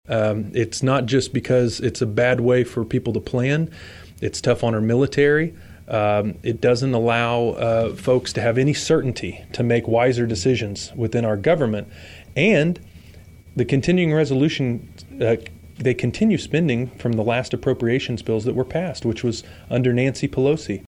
Congress narrowly averted a government shutdown recently — again — and Kansas Congressman Jake LaTurner used that as a start-off point on several policy concerns during an interview airing Friday on KVOE’s Morning Show.